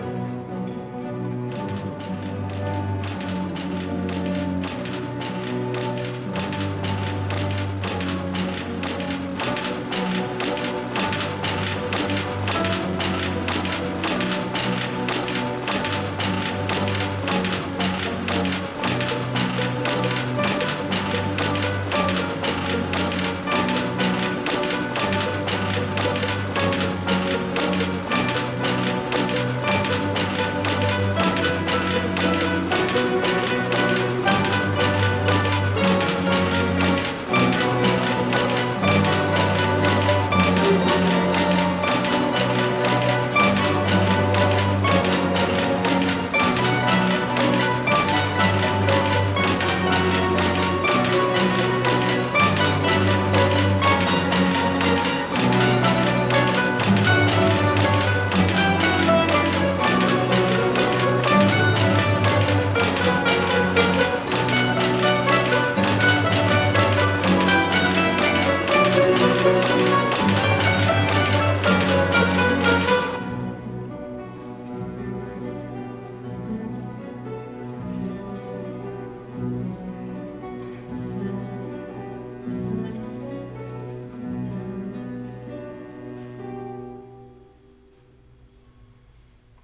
Museumnacht (Museumfabriek Enschede) 2 november 2024 – Twents Mandoline en Gitaar Orkest
In de Museumnacht van 2024 speelde TMGO tussen 19.00 en 0.00 uur bij de weefgetouwen in de Museumfabriek.
Nadat de weefgetouwen waren gedemonstreerd nam TMGO het stokje over, door in het ritme van het weefgetouw een stukje uit Run van Ludovico Einaudi te spelen.
Om te horen hoe het klonk met het weefgetouw, klik op onderstaande audiospeler: